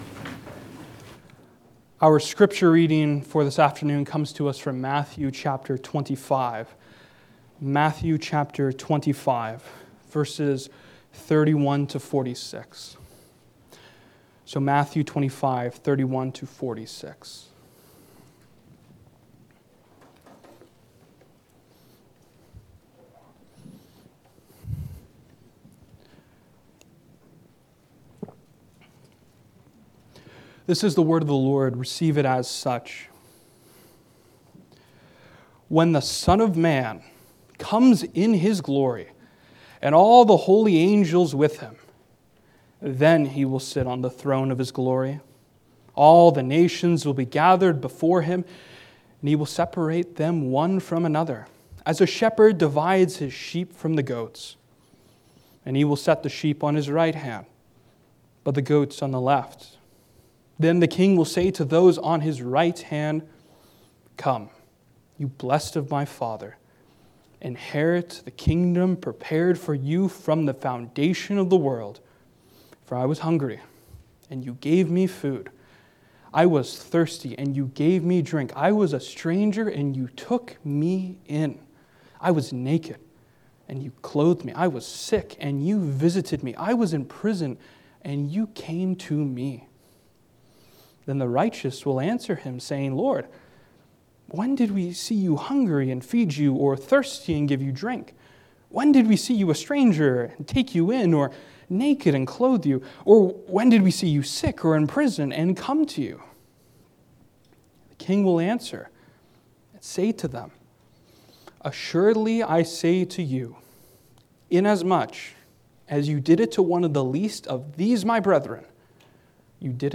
Passage: Matthew 25:31-46 Service Type: Sunday Afternoon